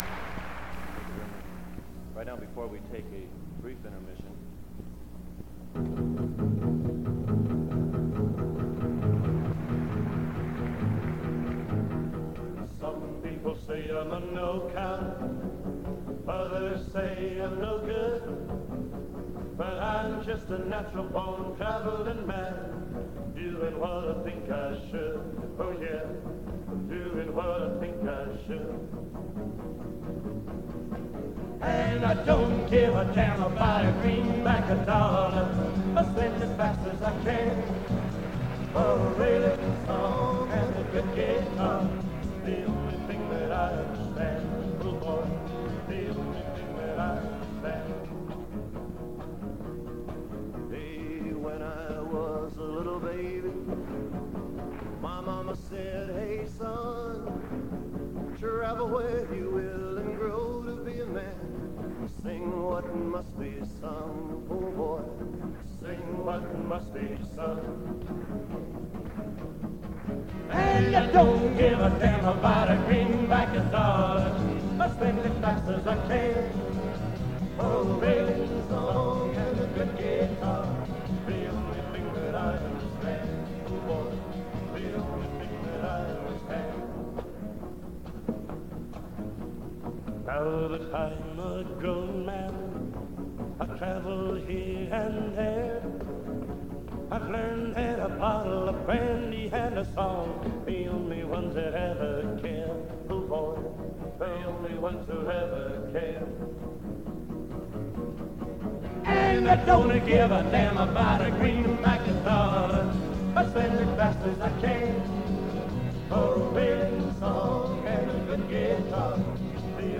This is a bootleg of a concert from sometime in 1963.